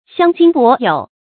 相驚伯有 注音： ㄒㄧㄤ ㄐㄧㄥ ㄅㄛˊ ㄧㄡˇ 讀音讀法： 意思解釋： 伯有：春秋時鄭國大夫良霄的字，相傳他死后鬼魂作祟。